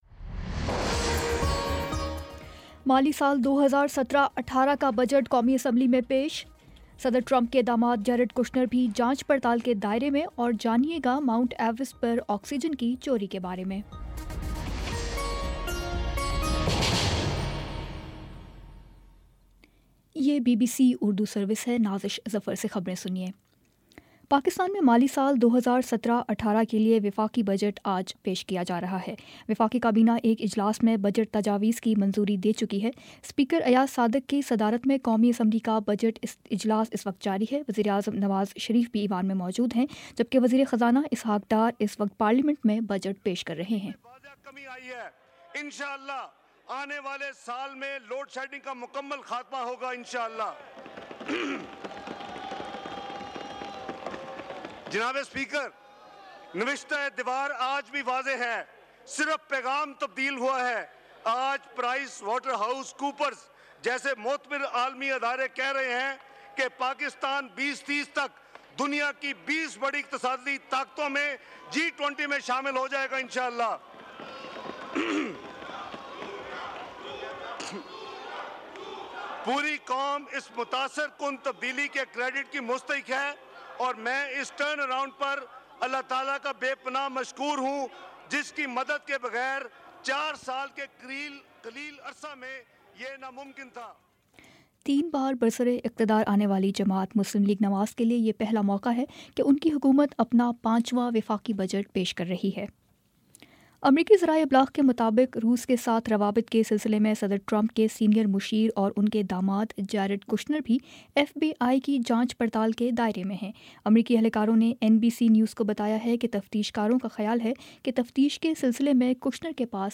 مئی 26 : شام پانچ بجے کا نیوز بُلیٹن